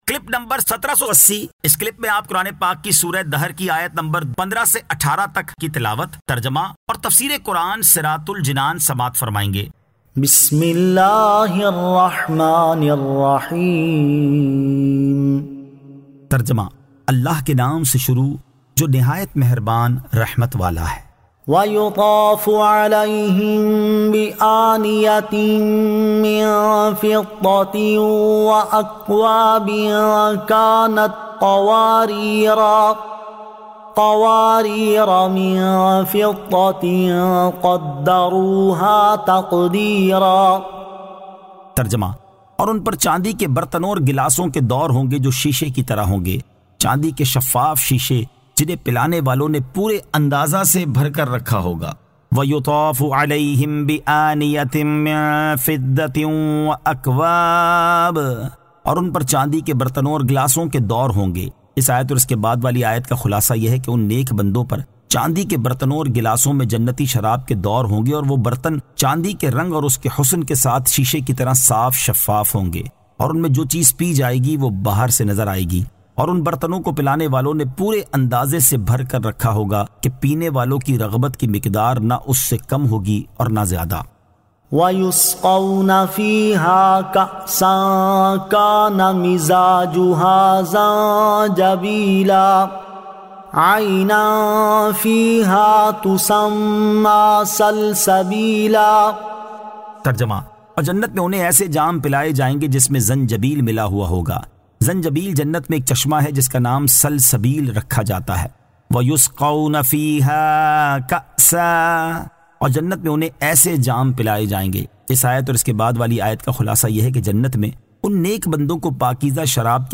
Surah Ad-Dahr 15 To 18 Tilawat , Tarjama , Tafseer